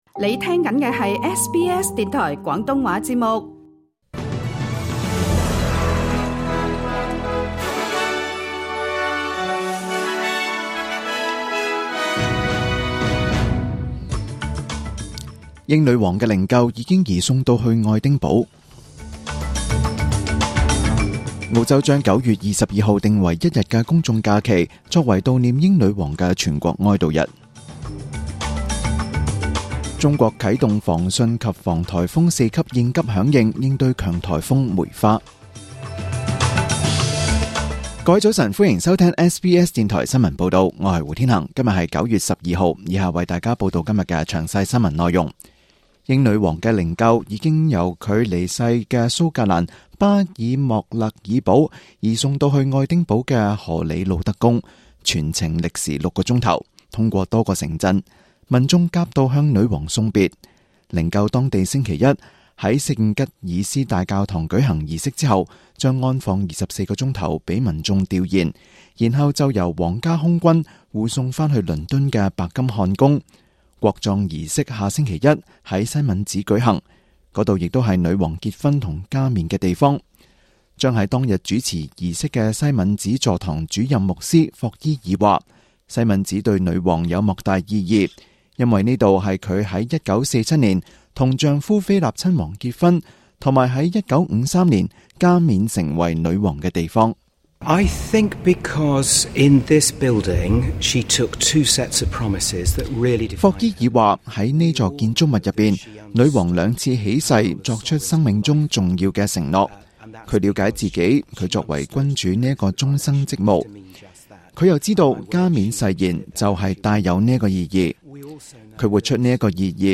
廣東話節目中文新聞 Source: SBS / SBS Cantonese